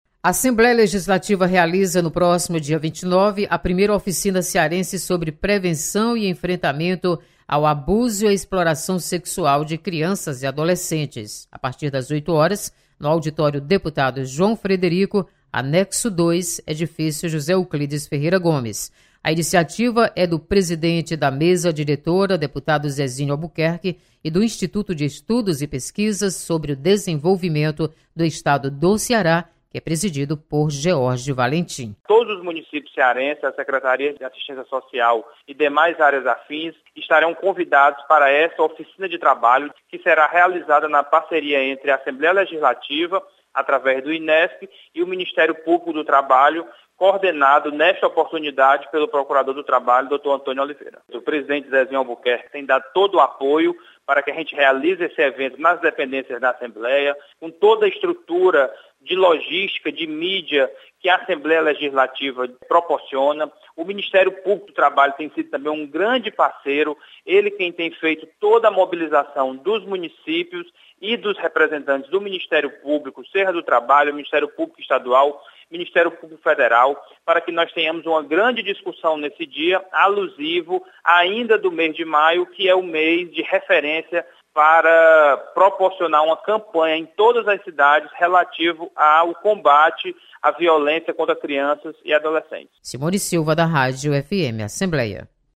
Abuso e exploração sexual de crianças e adolescentes é tema de oficina na Assembleia Legislativa. Repórter